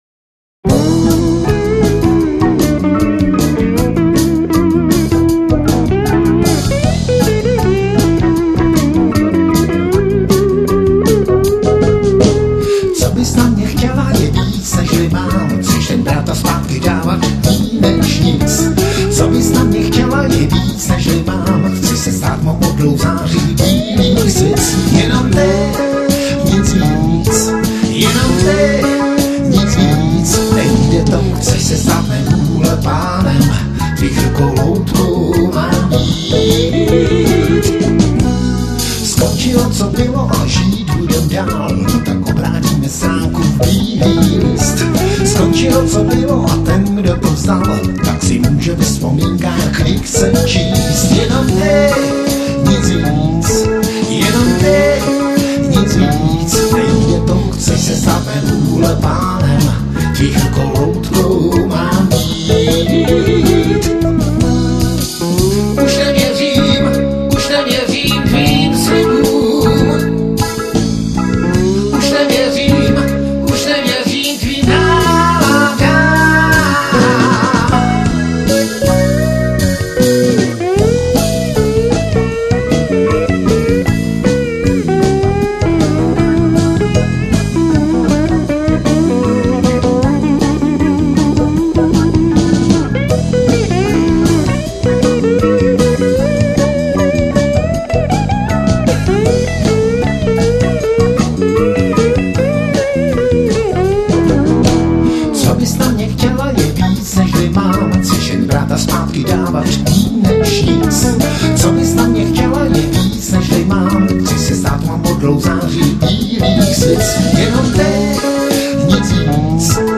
POHODA rock
Bicí
Klávesy
Sólova kytara